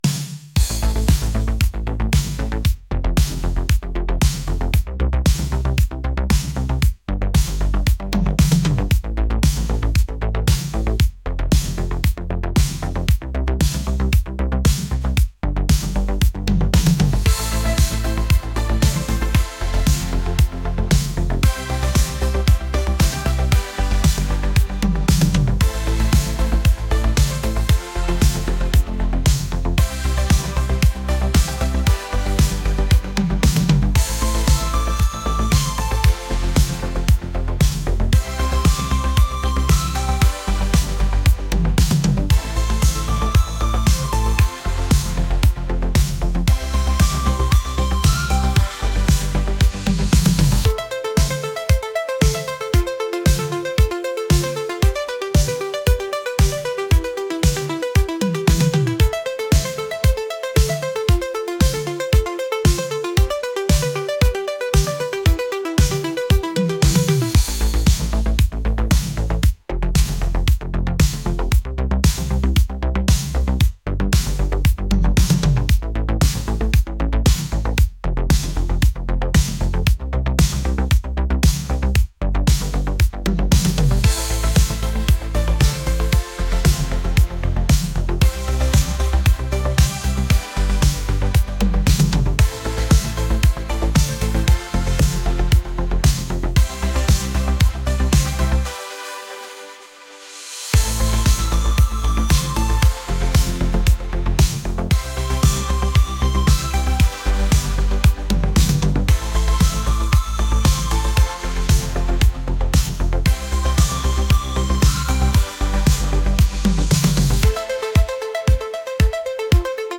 pop | retro | rock